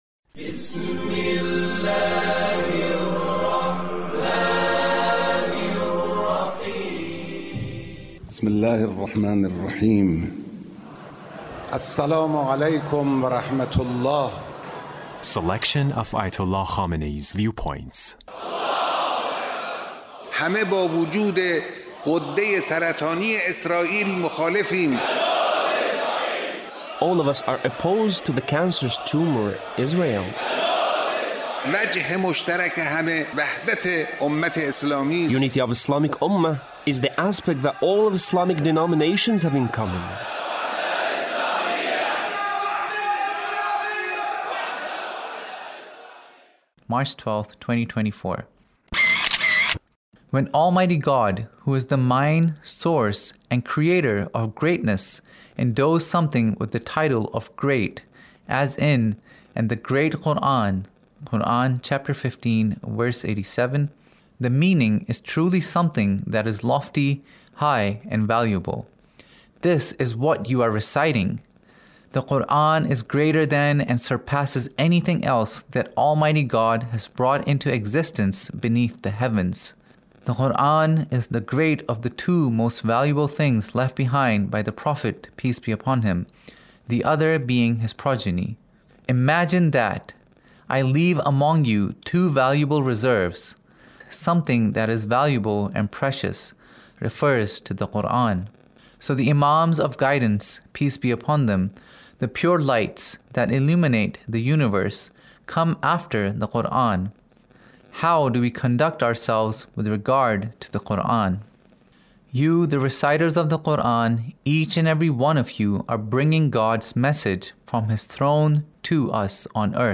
Leader's Speech Of The Quranic Reciters